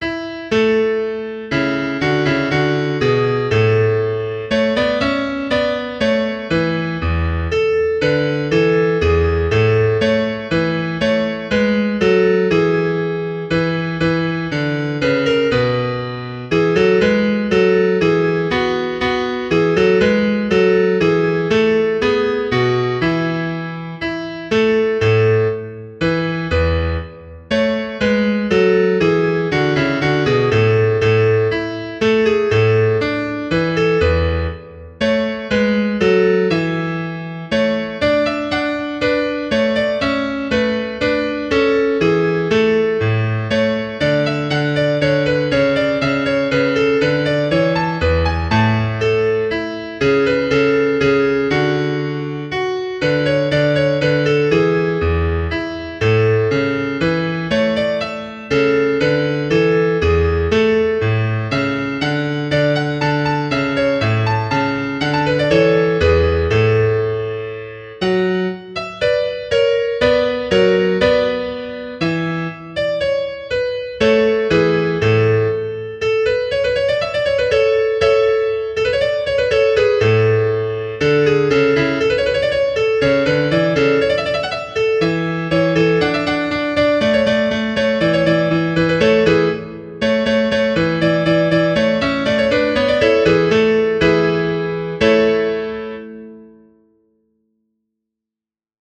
Key: A Major